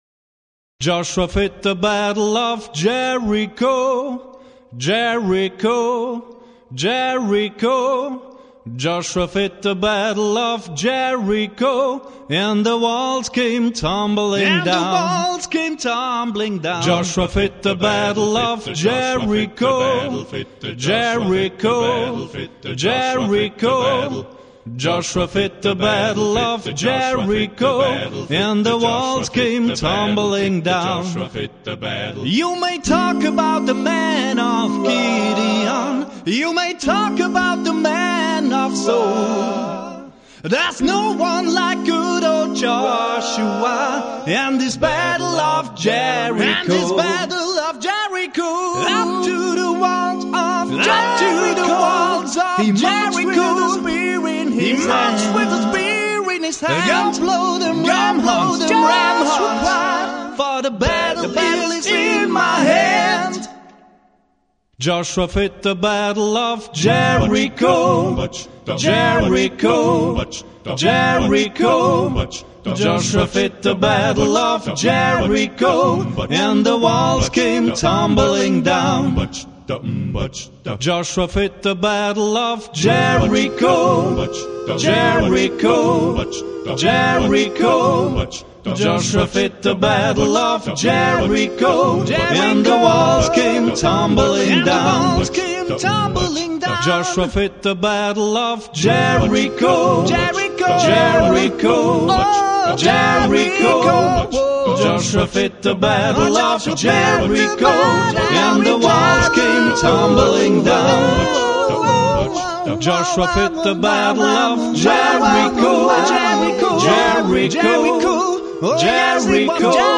7-stimmiges Acappella-Arrangement, Studioproduktion 2002
Vocals